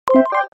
دانلود آهنگ کلیک 1 از افکت صوتی اشیاء
جلوه های صوتی
دانلود صدای کلیک 1 از ساعد نیوز با لینک مستقیم و کیفیت بالا